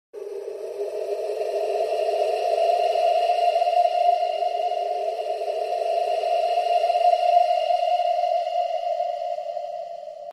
Creepy Surrounding